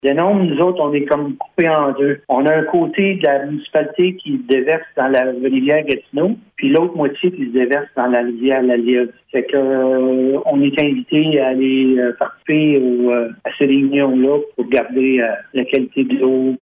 Le maire, Pierre Nelson Renaud, explique pourquoi ils ont dû nommer un représentant sur ce comité qui couvre une petite partie de la MRC de la Vallée-de-la-Gatineau :